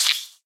Minecraft Version Minecraft Version snapshot Latest Release | Latest Snapshot snapshot / assets / minecraft / sounds / mob / silverfish / hit3.ogg Compare With Compare With Latest Release | Latest Snapshot